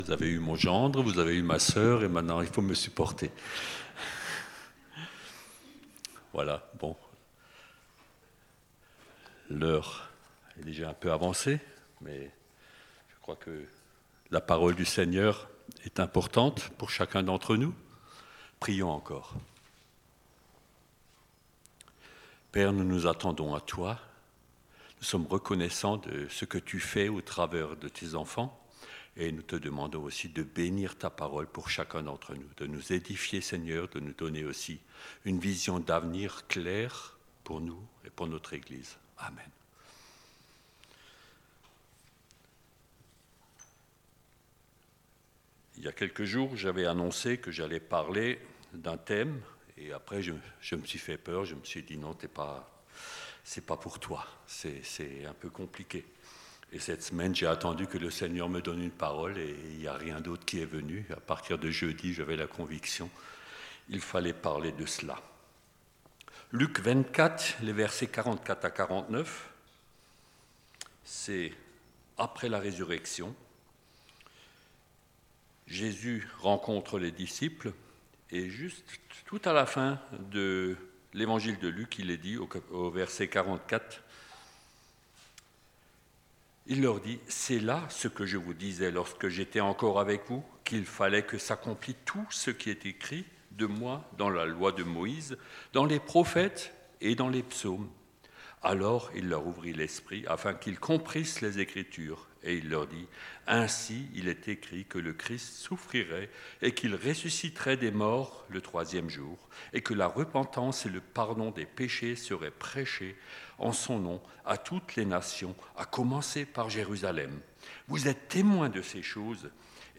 Les mages. culte de noel 2024.
Ecoutez les différents messages de l'église évangélique de Bouxwiller